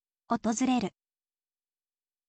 otozureru